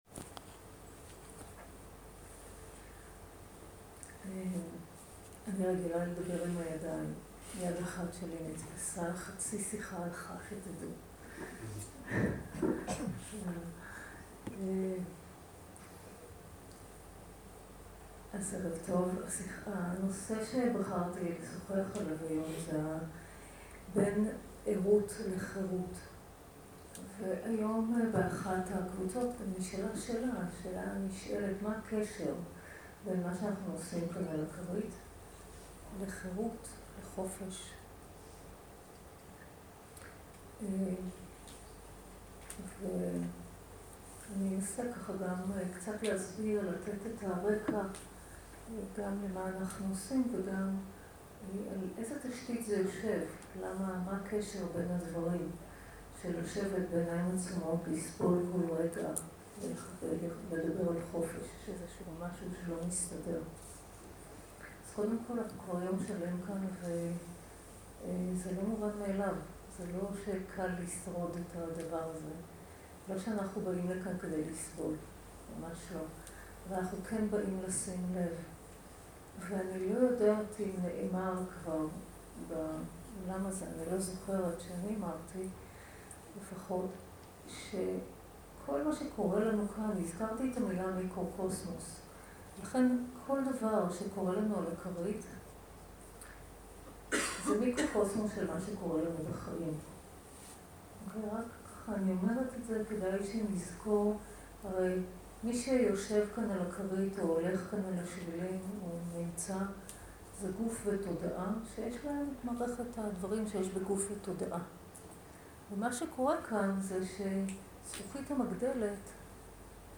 Dharma talk language